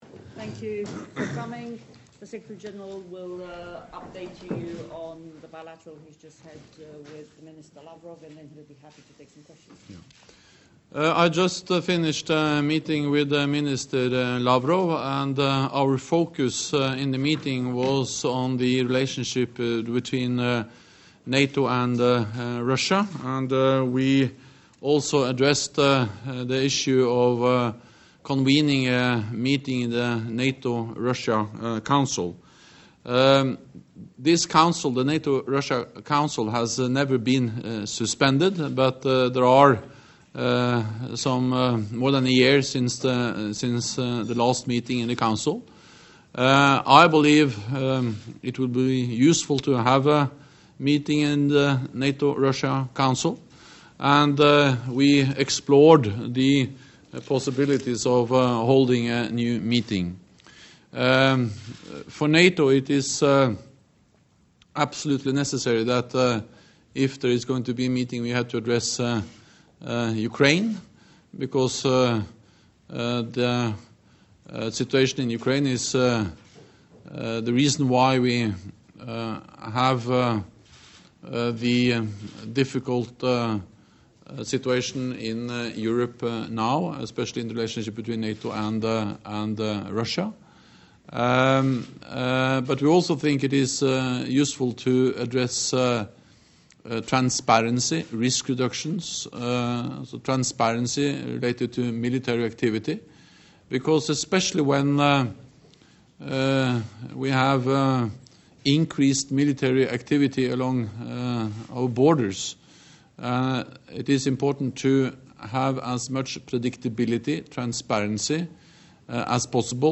Statement to the media by NATO Secretary General Jens Stoltenberg after his meeting with Foreign Minister Lavrov of Russia
NATO Secretary General Jens Stoltenberg talks to the media following his meeting with Foreign minister Lavrov of Russia